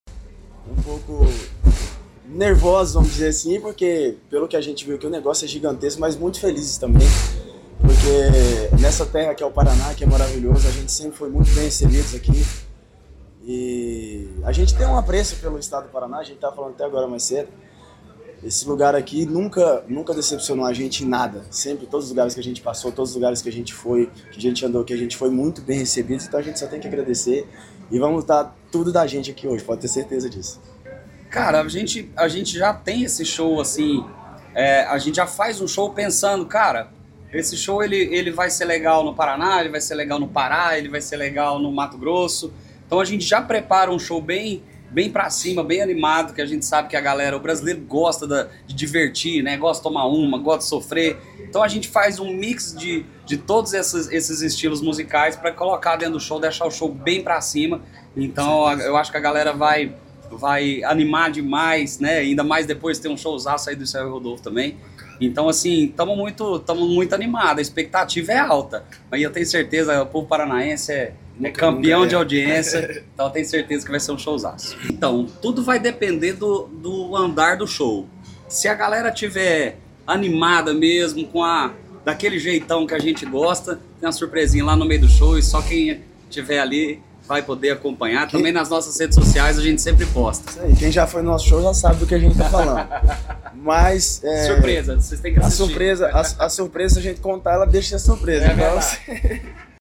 Sonora da dupla Bruno & Denner sobre o show desta sexta-feira pelo Verão Maior Paraná, em Pontal do Paraná